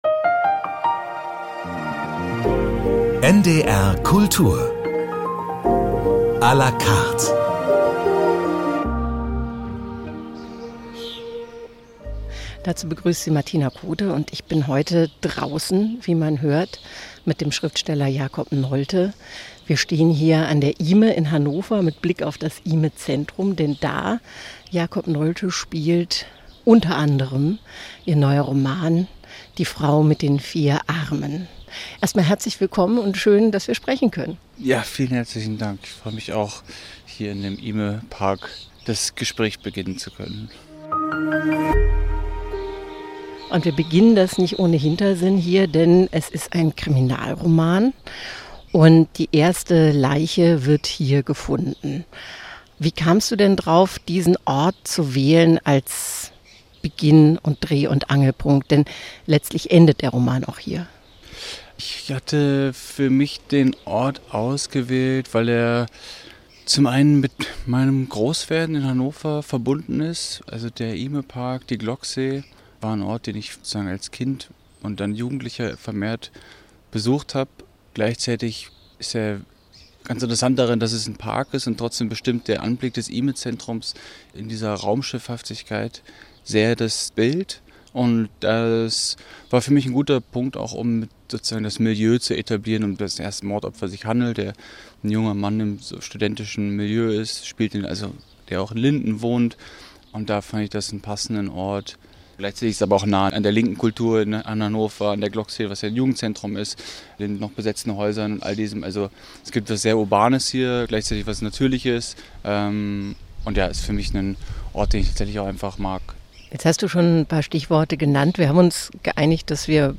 Lesung für Kinder: Der Piratenbarbier - 23.06.2024